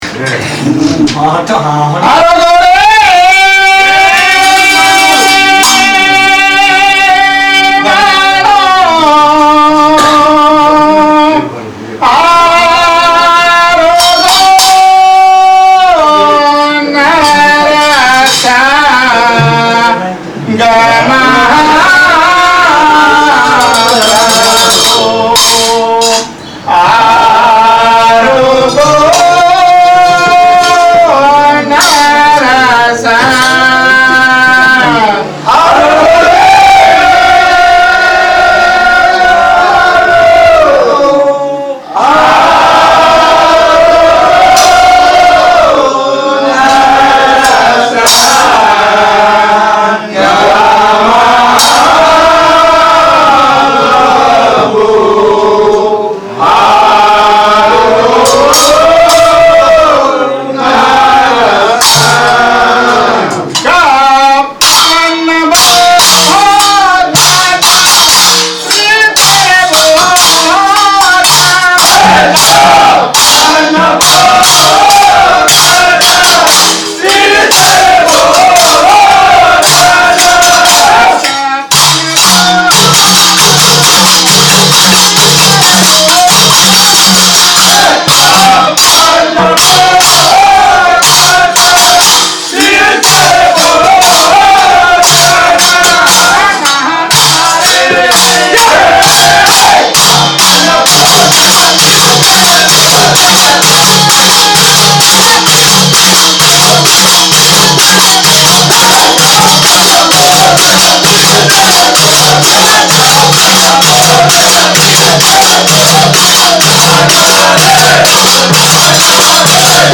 નાદબ્રહ્મ પદ - ૨૭૪, રાગ - ધનાશ્રીNādbrahma pada 274, rāga - dhanāshriઆરોગો નૃસિંહ ...